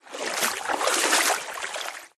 water-wade-03.ogg